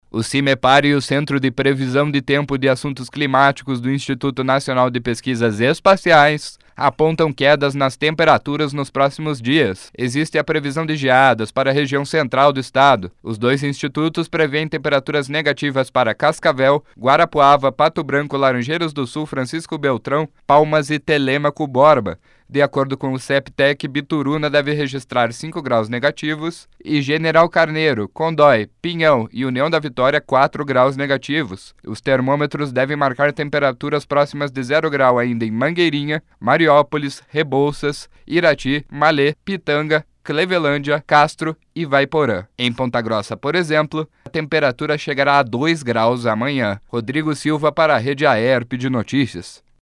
07.06 – BOLETIM SEM TRILHA – Geadas devem atingir várias cidades do Paraná nos próximos dias